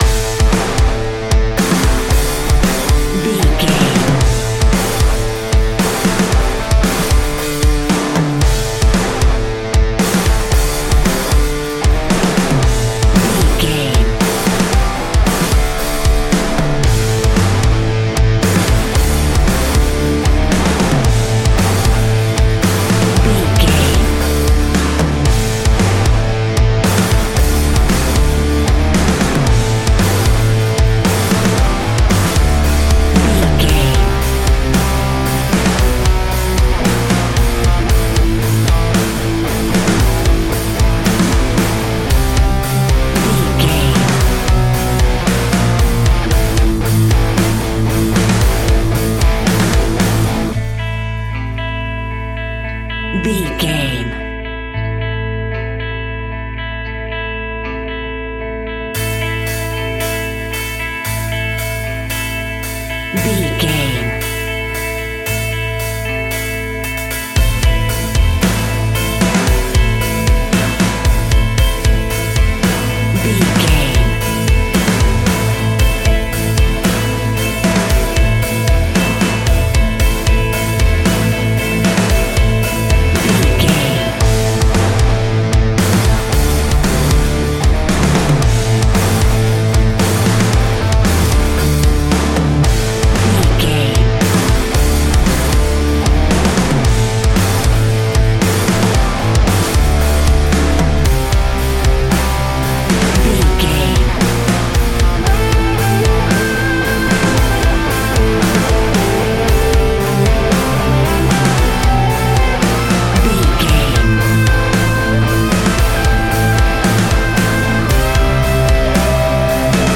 Ionian/Major
A♭
hard rock
guitars
instrumentals